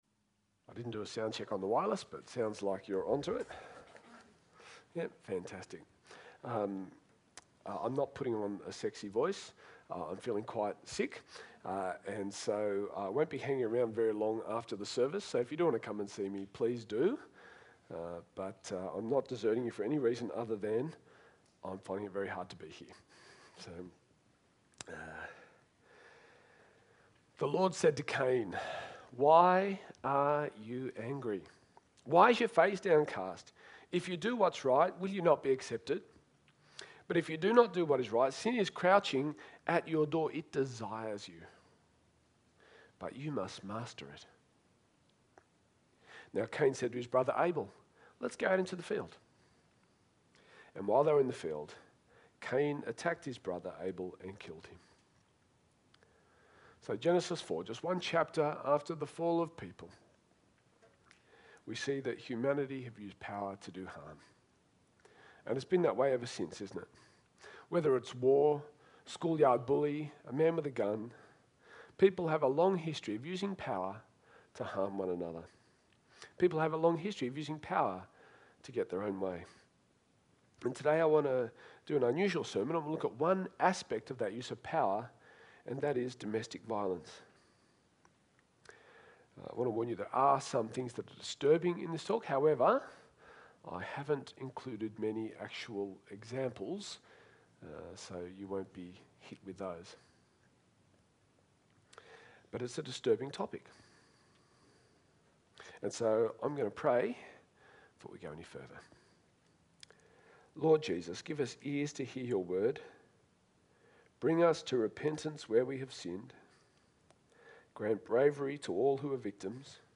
SERMON - Domestic Violence - Narellan Anglican Church